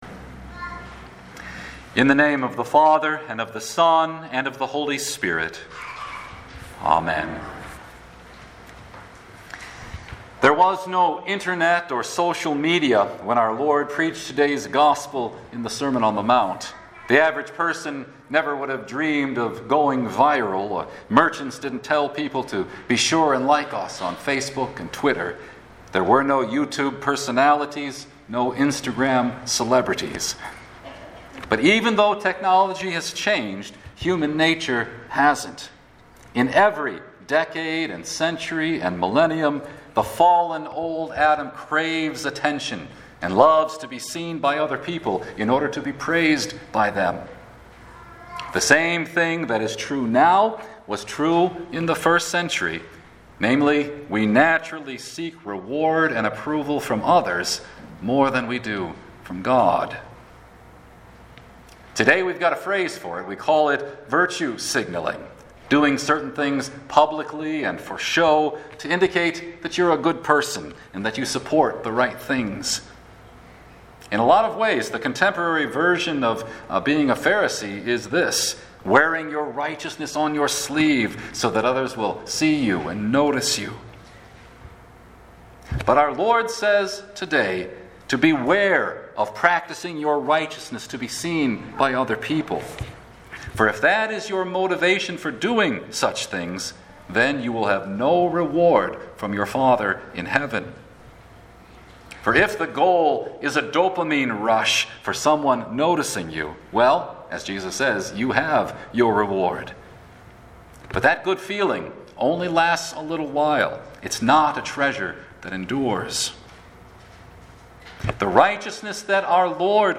Ash Wednesday Matt 6:1-6, 16-21